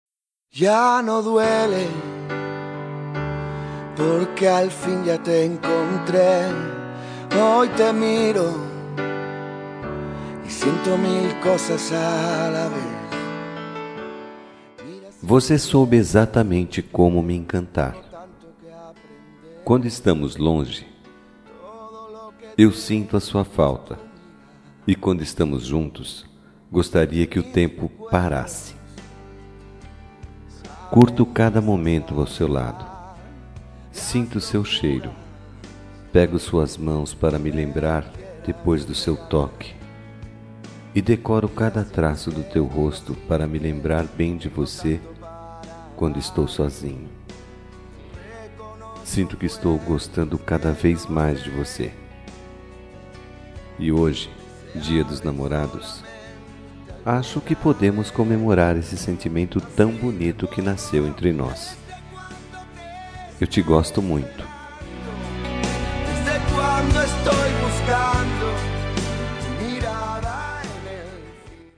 Telemensagem Dia Dos Namorados Ficante
Voz Masculina